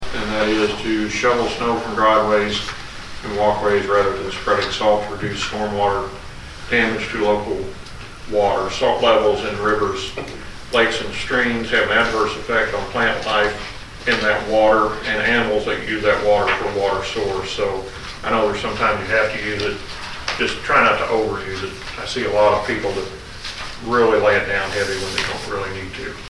Helpful hints and recommendations were made by Marshall City Councilmen during the council meeting on Monday, February 1.
Ward 3 Councilman Dan Brandt offered the “Stormwater Tip of the Month.”